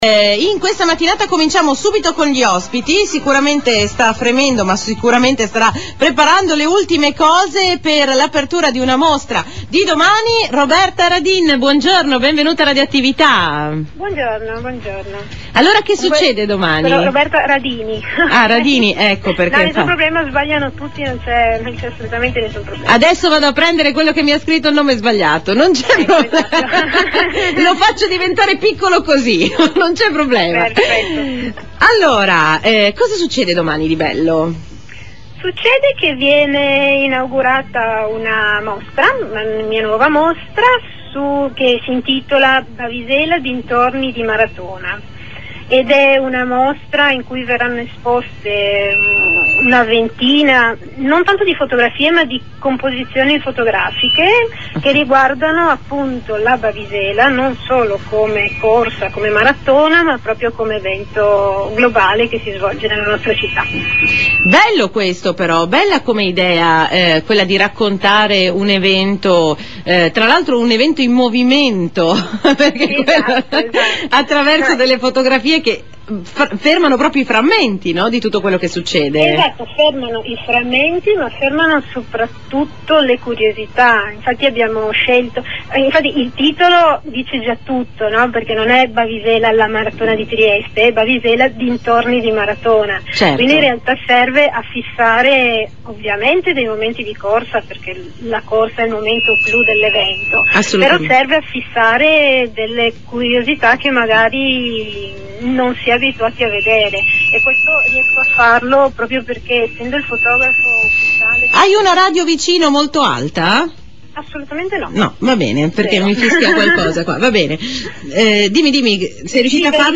Intervista su RadioAttività (febbraio 2011)